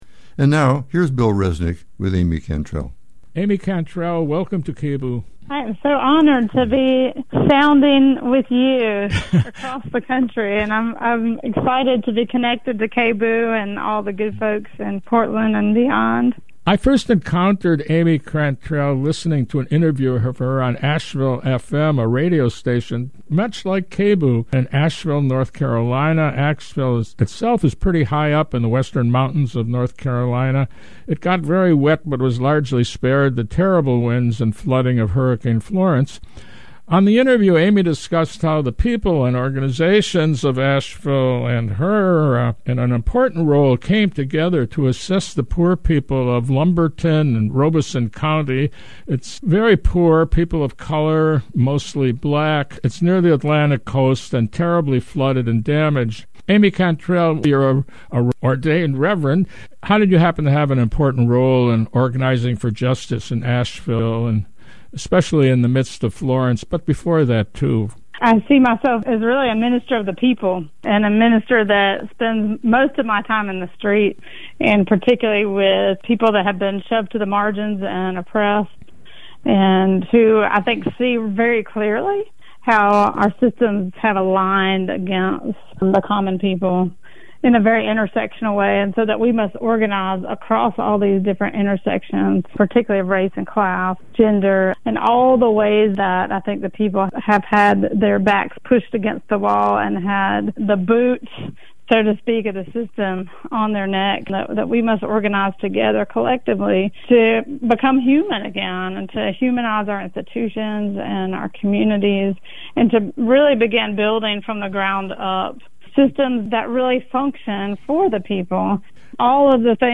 INTERVIEW ABOUT MUTUAL AID DURING TIMES OF DISASTER